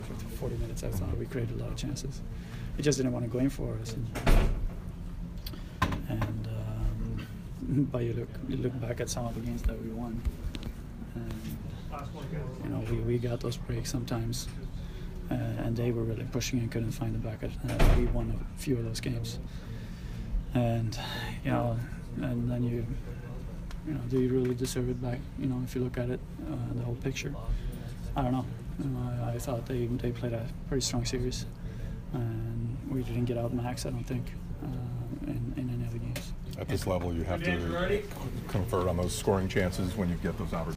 Anton Stralman post-game 5/23